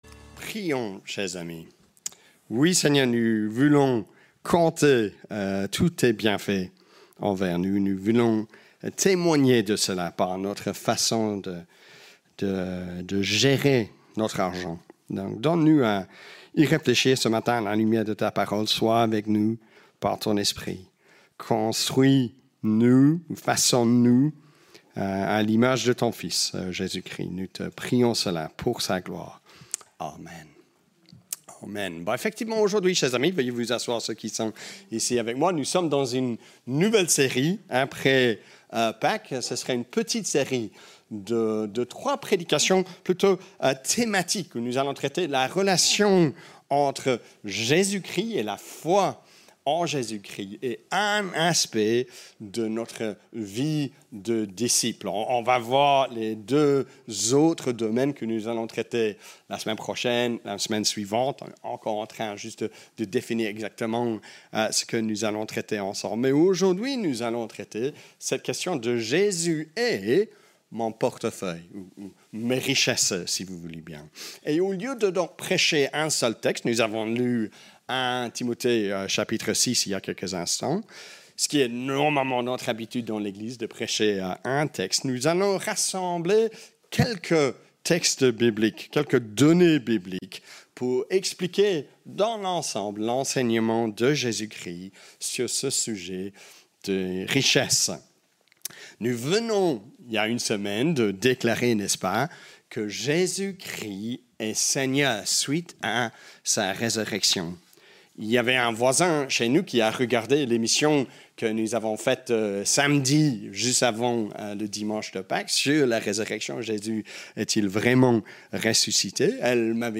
Jésus et...mon portefeuille | Église Protestante Évangélique de Bruxelles-Woluwe